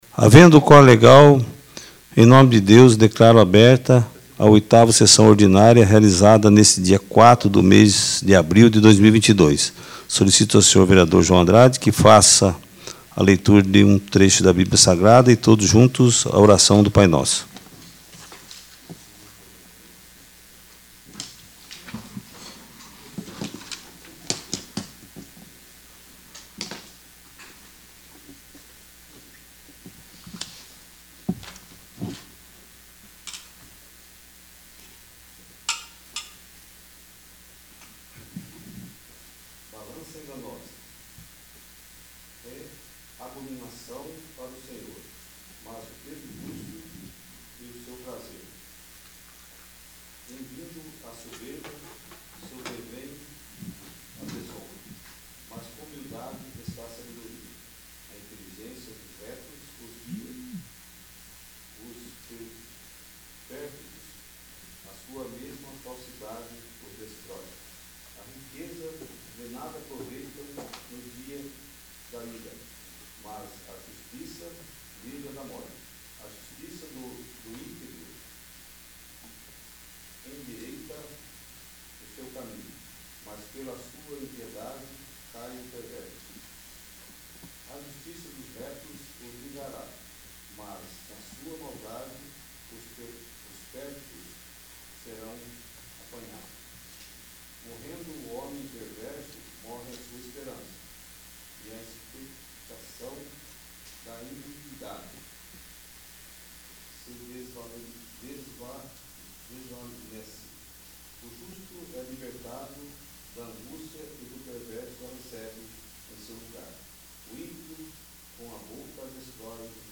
8º. Sessão Ordinária